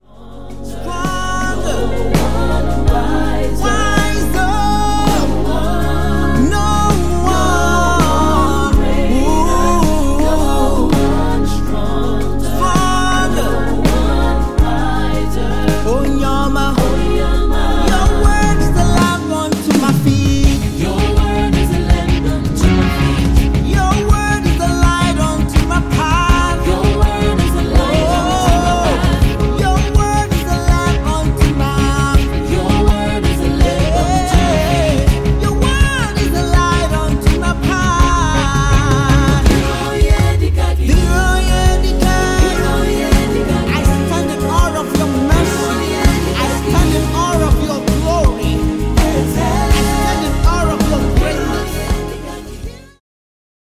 Canada-based Recording artist, Songwriter and Gospel Singer.
New single Out Now!